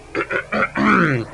Clear Throat Sound Effect
Download a high-quality clear throat sound effect.
clear-throat-2.mp3